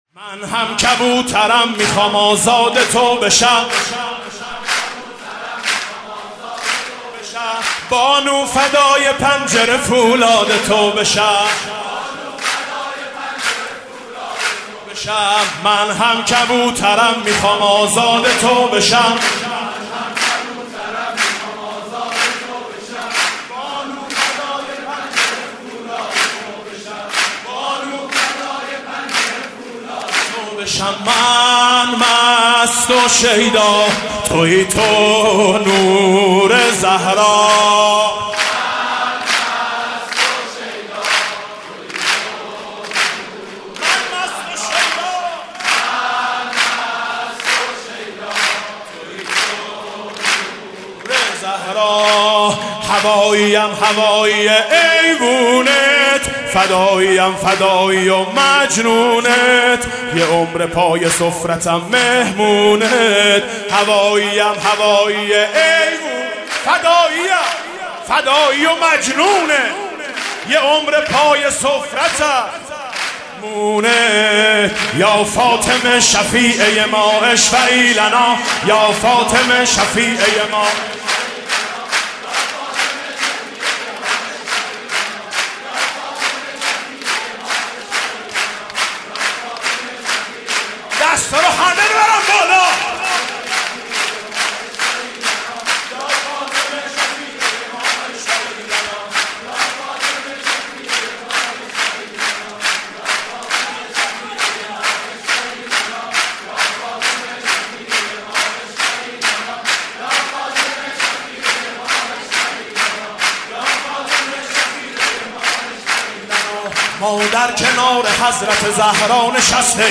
سرود: یا فاطمه شفیعه‌ی ما اشفعی لنا